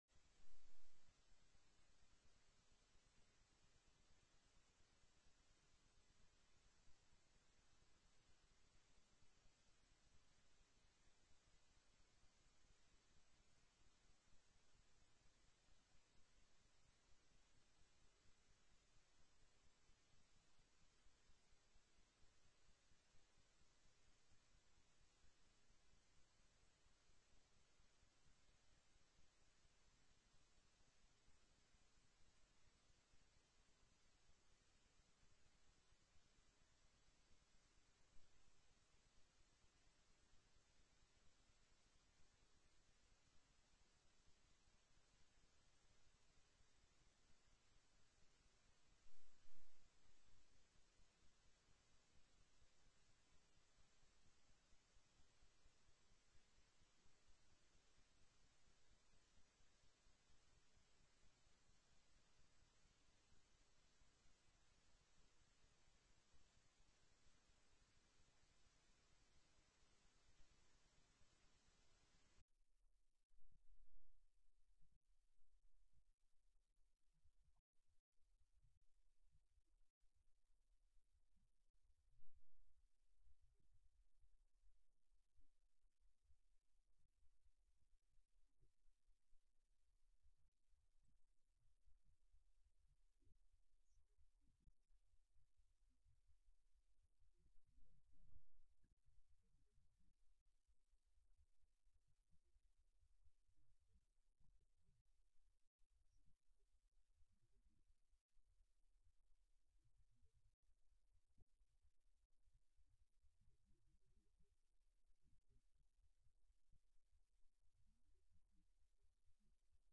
05/05/2007 09:00 AM House FINANCE